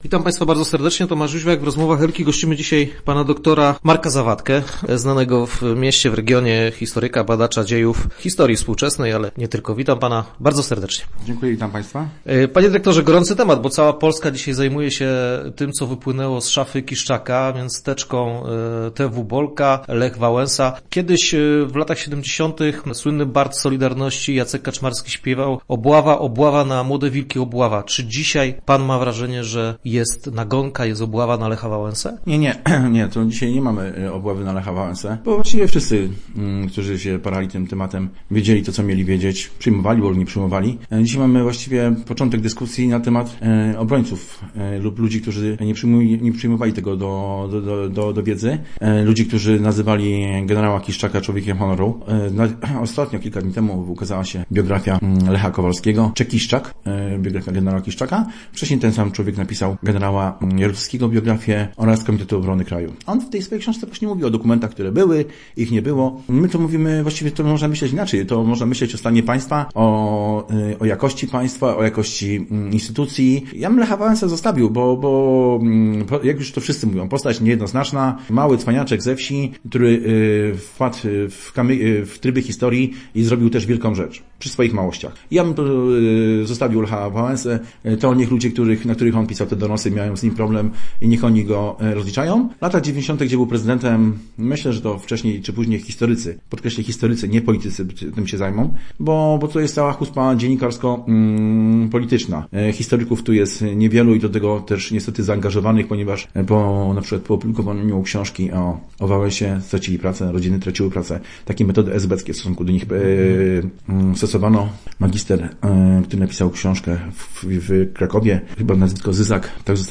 Naszym go�ciem by� znany w regionie historyk